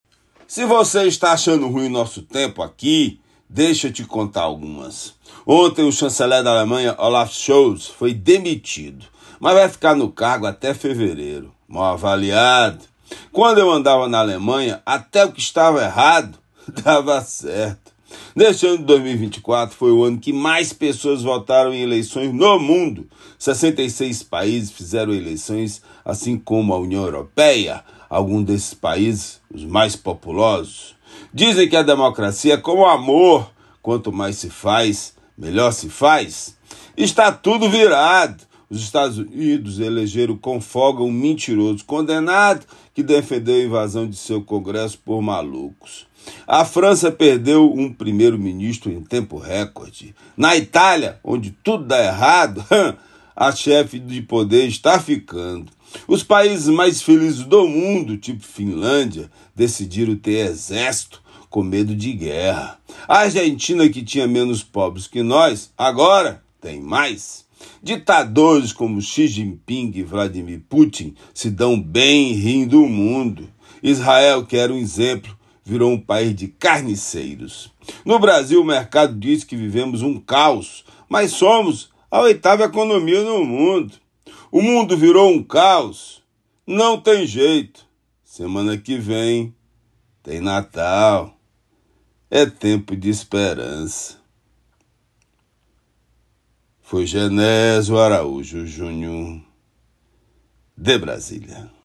Comentário
direto de Brasília.